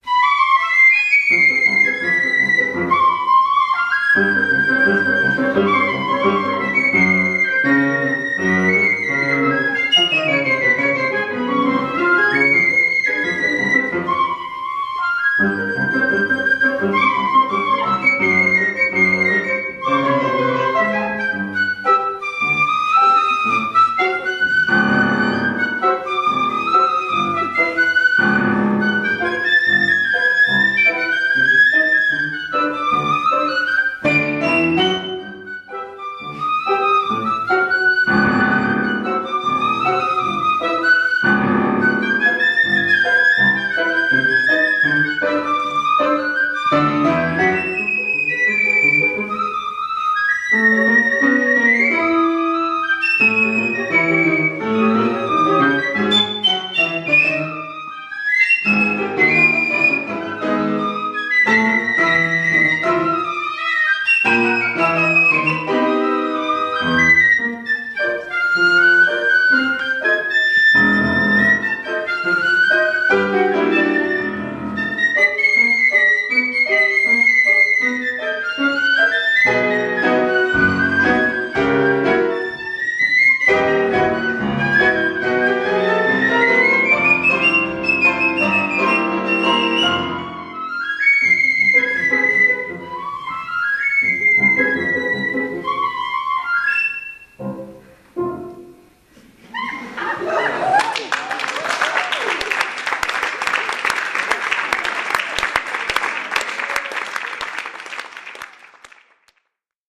zongorista